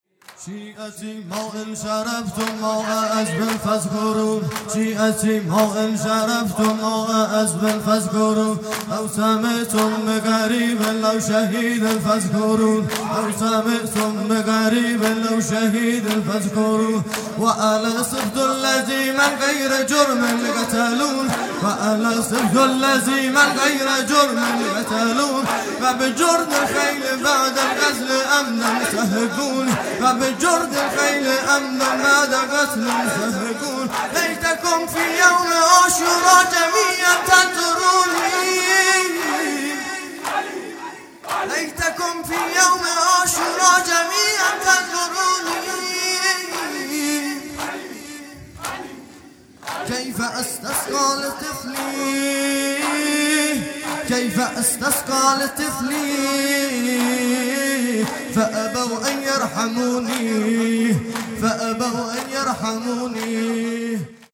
جلسه هفتگی ۴ دی ۹۷